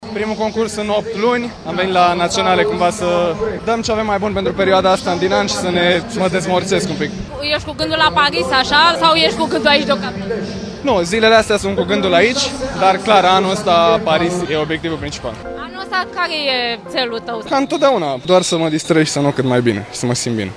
Într-un interviu acordat TVR Sport, David Popovici a vorbit despre obiectivele sale pentru anul acesta.